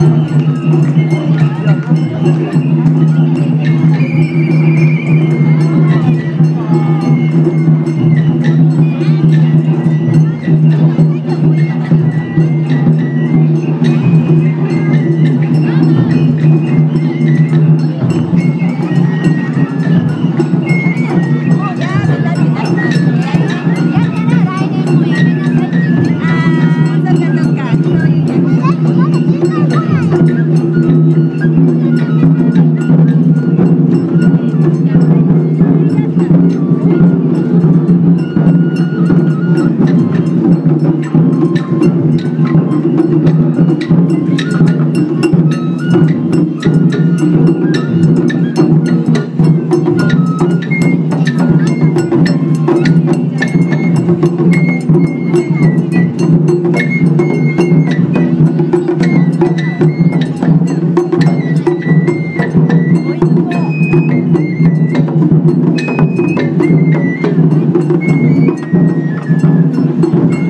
Kunitachi Festival